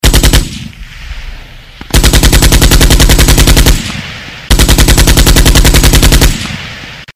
Звук немецкого автомата с очередью выстрелов